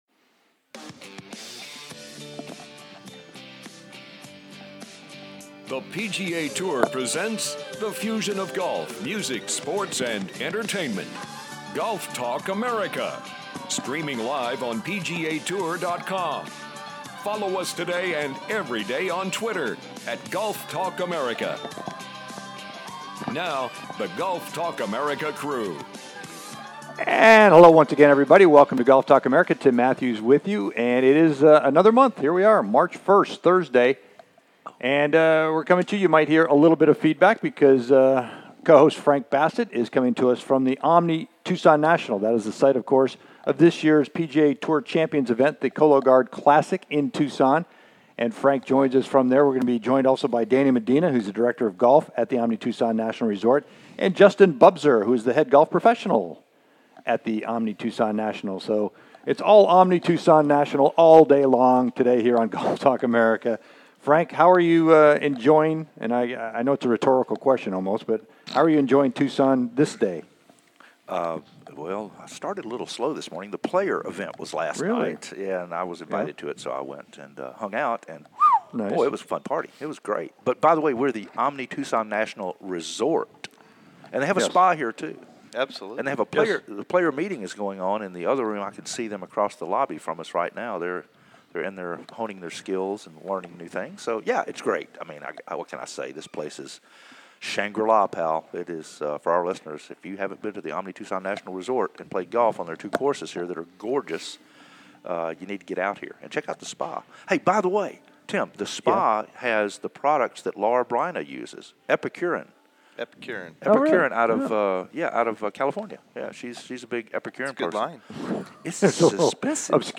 "LIVE" Again From The Tucson National Resort & The Cologuard Classic